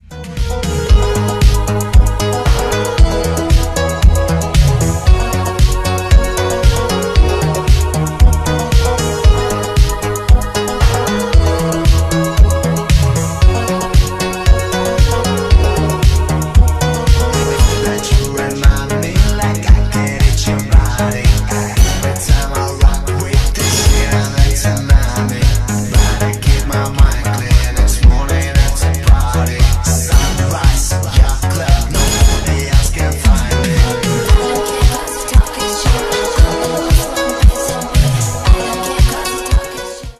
диско
ремиксы